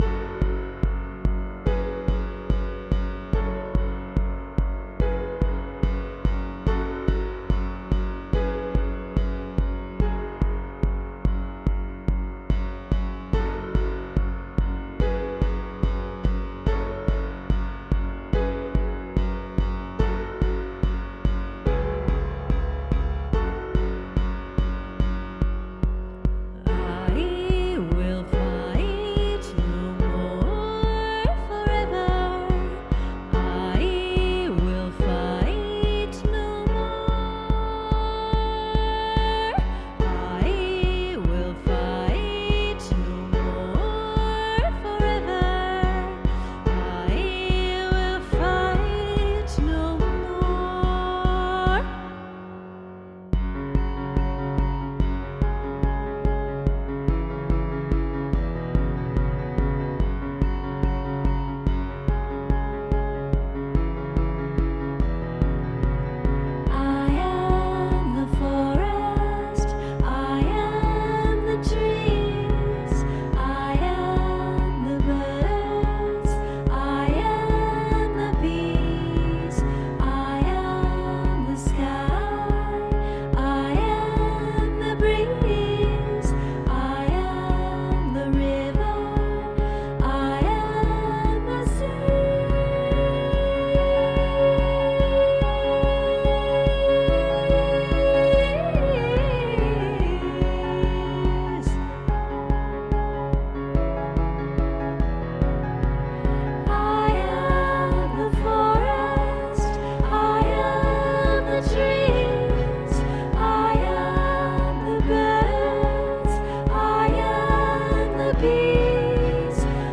vox, keyboards, hammered dulcimer